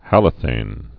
(hălə-thān)